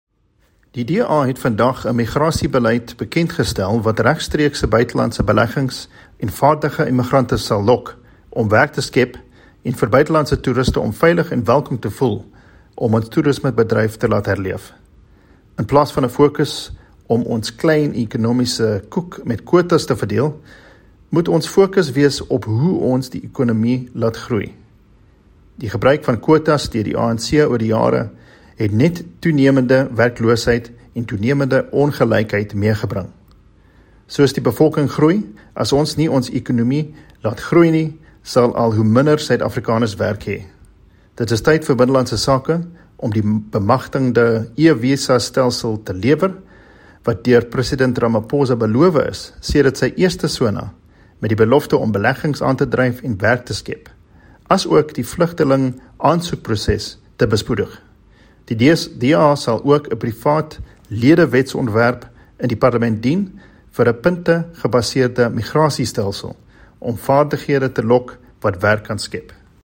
Please find attached soundbites by Gwen Ngwenya DA Head of Policy;